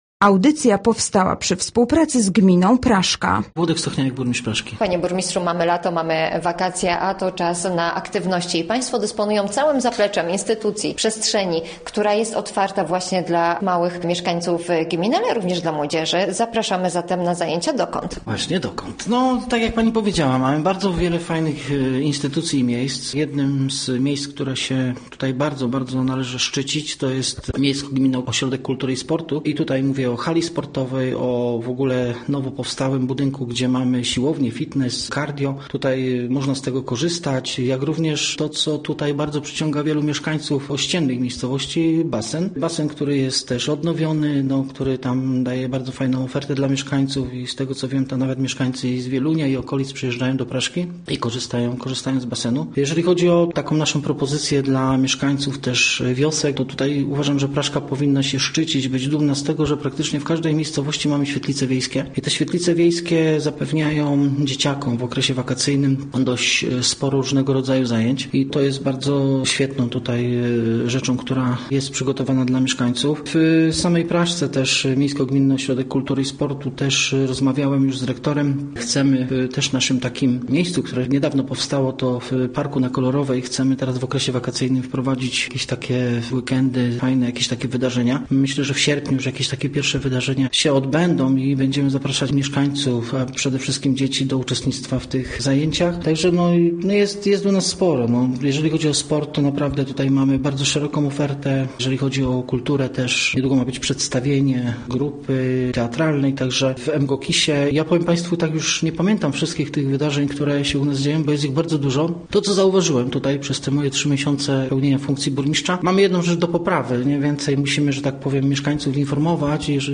Gościem Radia ZW był burmistrz Praszki, Włodzimierz Stochniałek